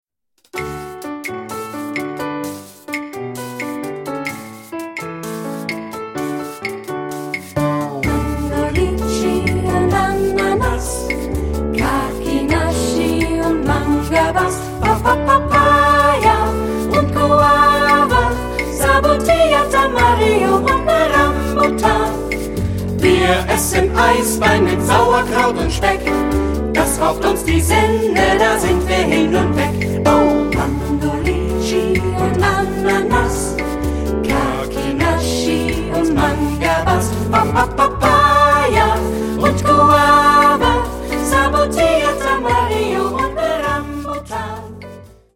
Kanon ; Choraljazz
jazzy ; fröhlich ; leicht
Ad libitum (3 Ad libitum Stimmen )
Tonart(en): D-Dur